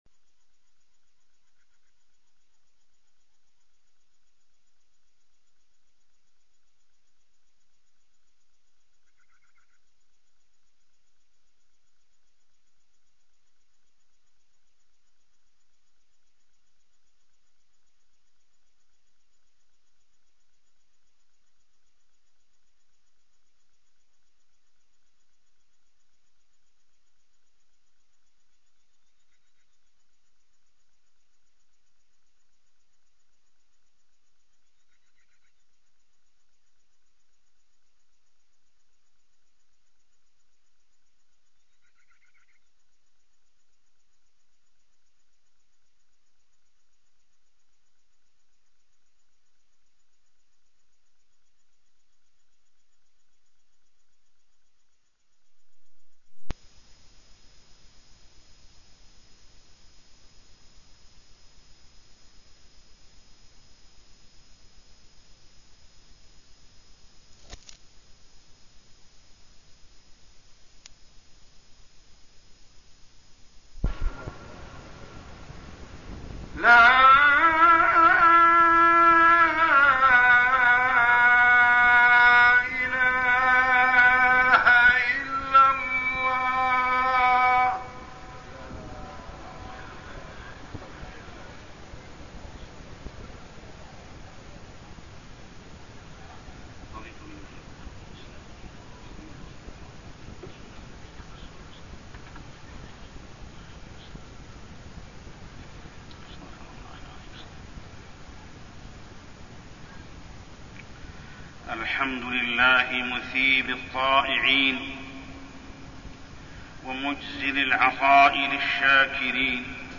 شكر النعم ( خطبة ) - الشيخ محمد السبيل